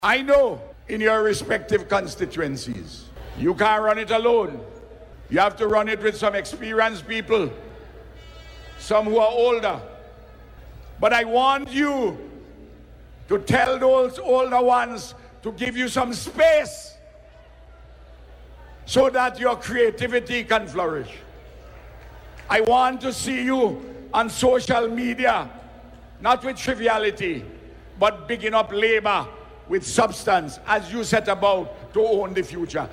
Hundreds of young people gathered on Sunday for the Unity Labour Party’s Youth Convention, an event aimed at empowering the next generation of political leaders.
The convention featured several speakers, including Prime Minister, Dr. Ralph Gonsalves.